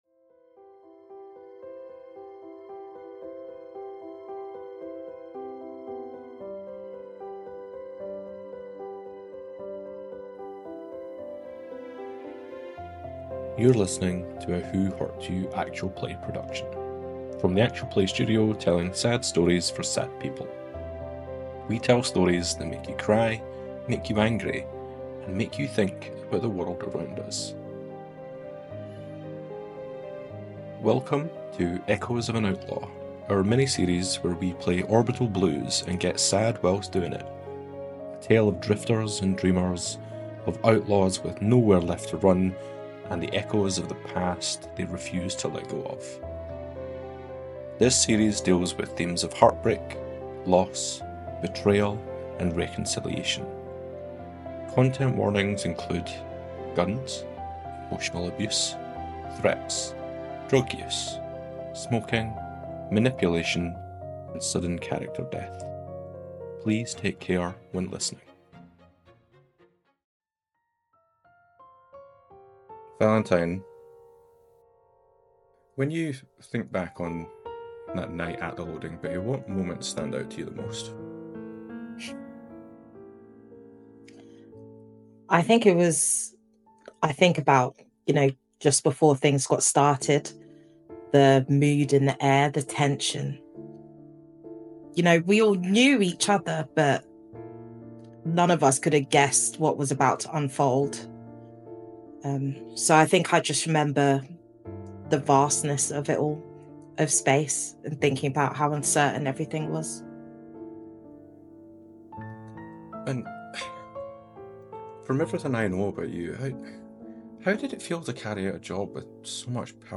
Who Hurt You? Actual Plays